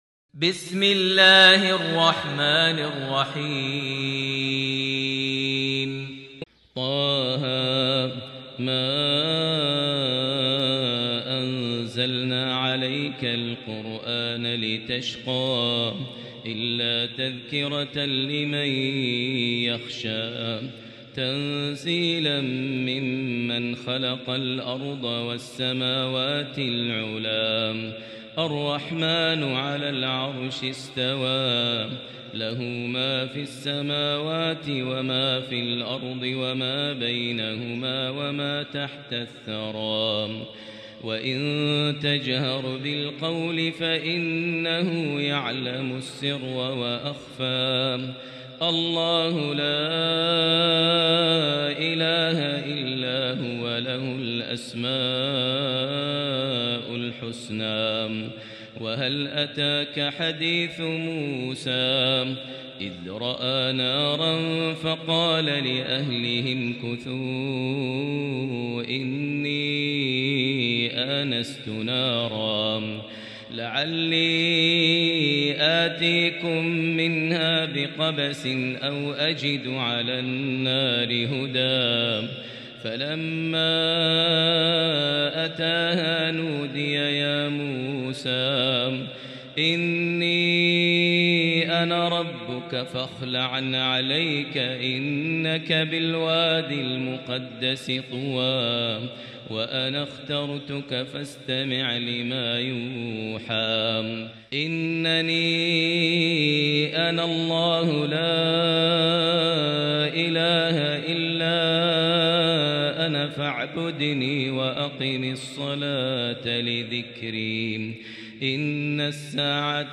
سورة طه "النسخة 1" > مصحف الشيخ ماهر المعيقلي (2) > المصحف - تلاوات ماهر المعيقلي